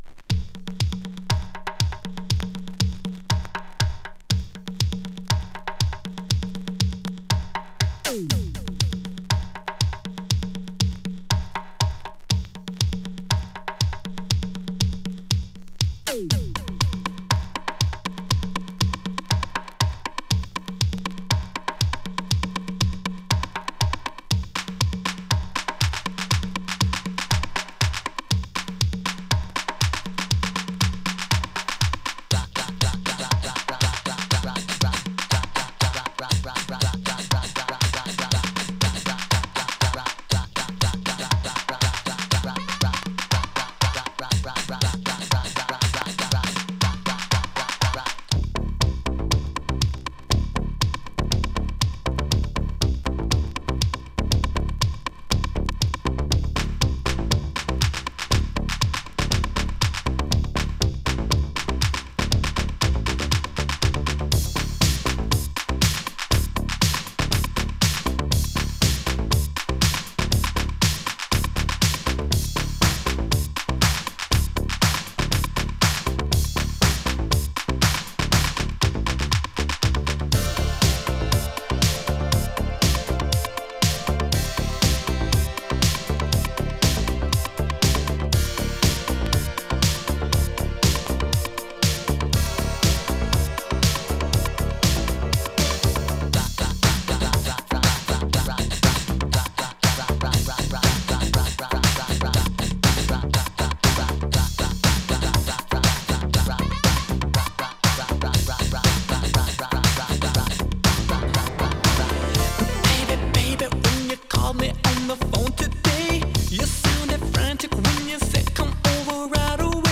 現在のR&Bに直結する80'Sアーバン・ダンス・クラシックス